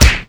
slap_hit2.wav